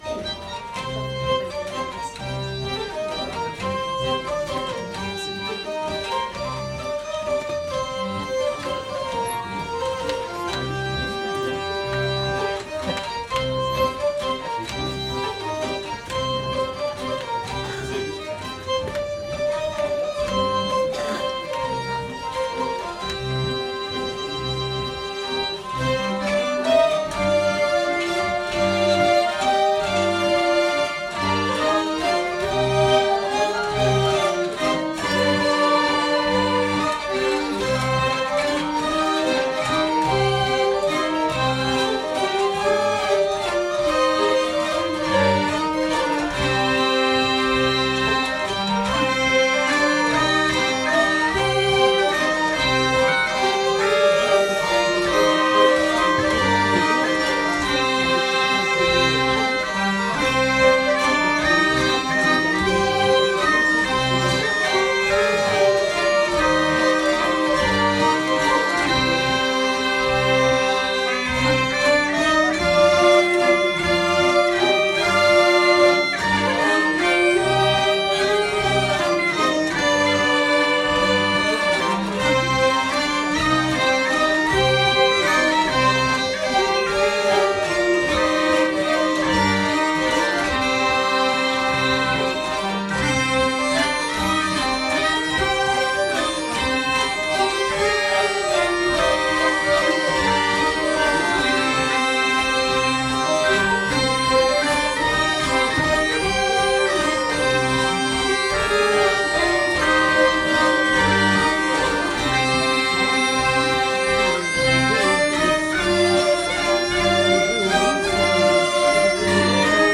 A partir de morceaux de musique collectés en Bresse, les musiciens ont travaillé des arrangements pour amener d’autres couleurs à ces mélodies. Les écritures musicales et la direction ont été confiées à un musicien joueur de vielle du Centre France.
Valse-de-Jouze-Bouva.mp3